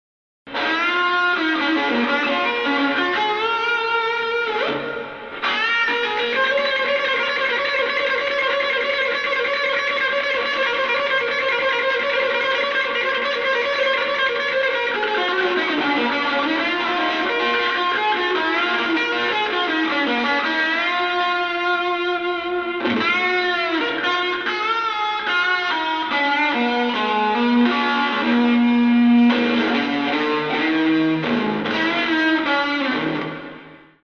Unveröffentlichtes Material und Mitschnitte aus dem Proberaum
Unveröffentlichtes Material und Mitschnitte aus dem Proberaum Achtung – geht unter Umständen auf die Ohren 😉 … also aufgepasst …. % Kleines Gitarrenintro